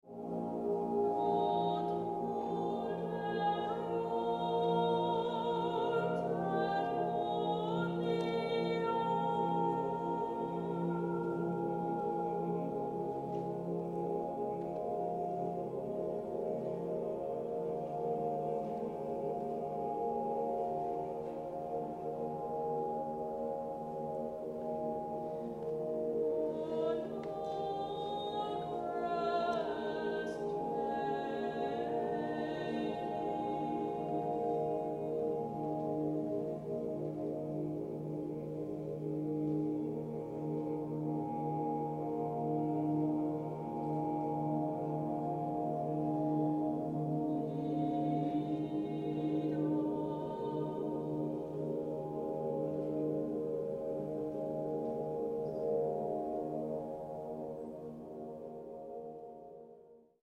Choir (SSAATTB) and computer disc